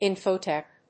/ˈɪnfoˌtɛk(米国英語), ˈɪnfəʊˌtek(英国英語)/